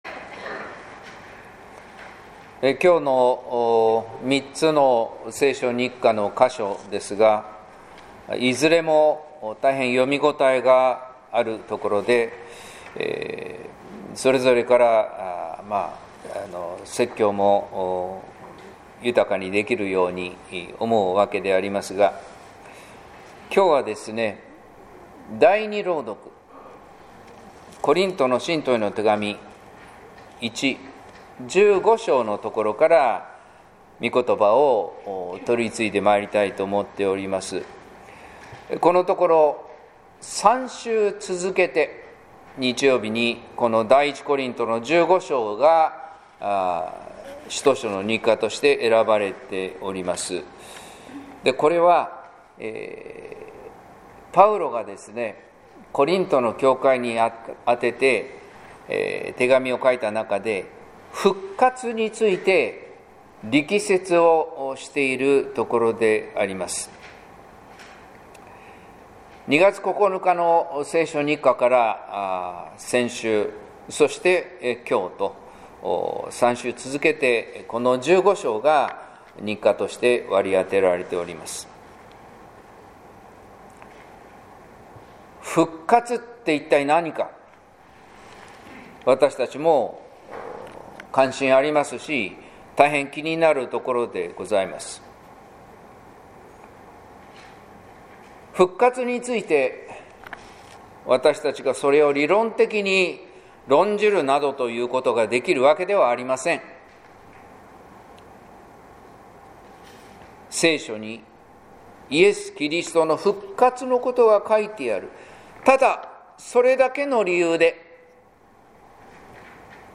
説教「復活はひとつの自然」（音声版）